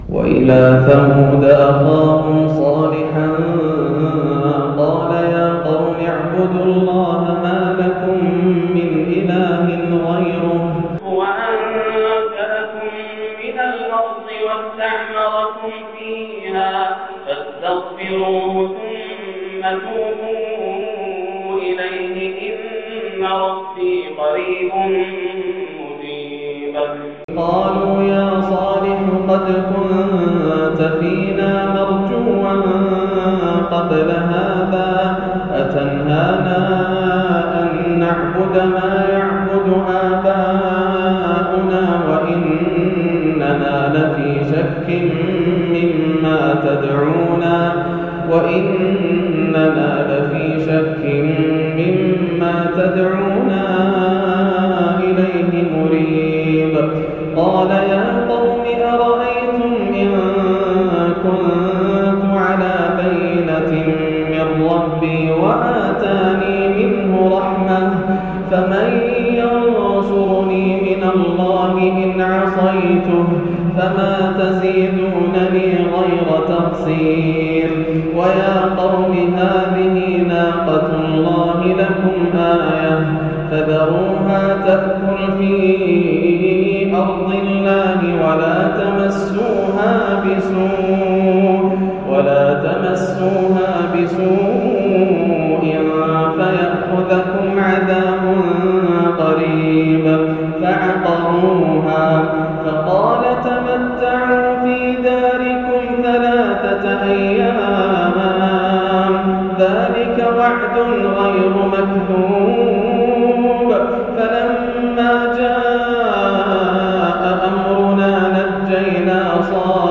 قراءة جميلة من سورة هود - عشاء 5-1430 > عام 1430 > الفروض - تلاوات ياسر الدوسري